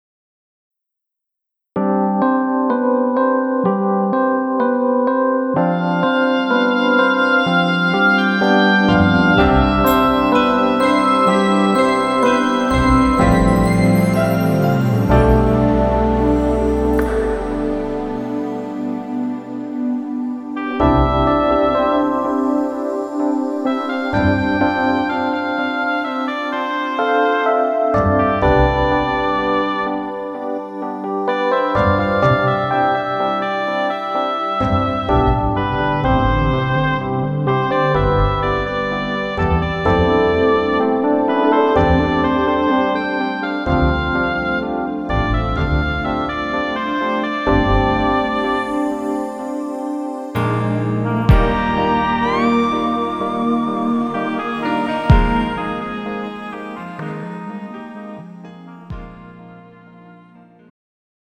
음정 멜로디MR
장르 축가 구분 Pro MR
가사 (1절만)